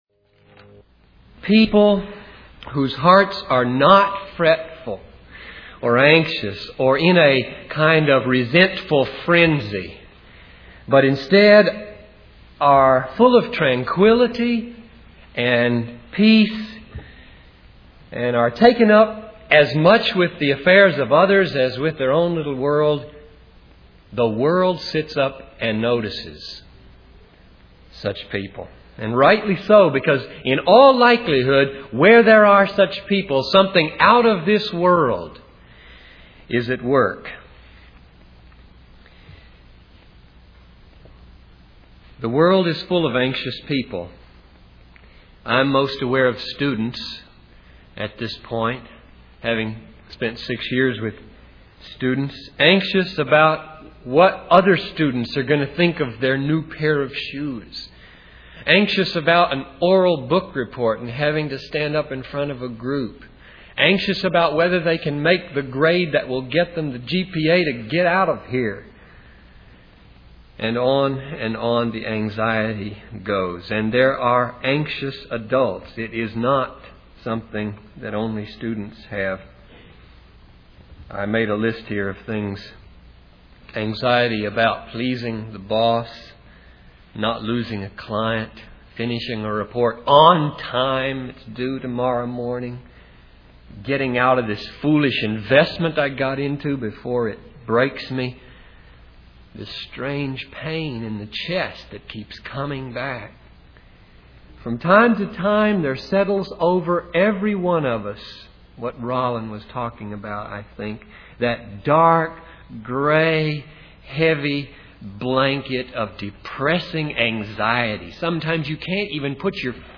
In this sermon, the speaker focuses on Psalm 127, specifically verses 1 and 2. The main point of the text is that unless the Lord is involved in our endeavors, our labor is in vain.